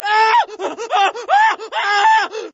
scream15.ogg